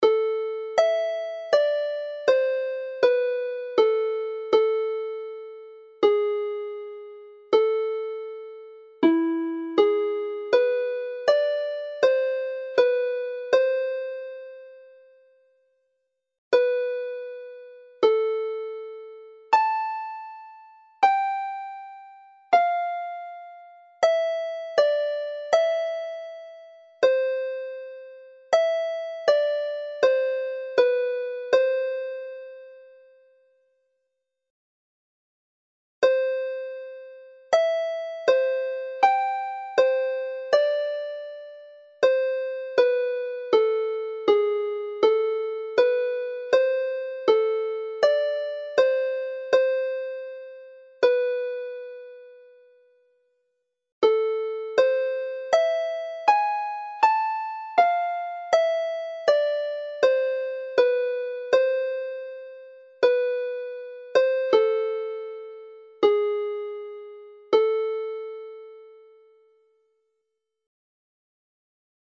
The tunes in this set use the typically Welsh minor scale. All are in A minor and have the tone-and-a-half interval from F natural to G sharp to produce the characterstic feel to the melody. This occurs in part A of the tune which then goes into C major for the second part with a G natural in part B before returning again to the minor key to repeat part A, typical of many Welsh traditional tunes.
Play the tune slowly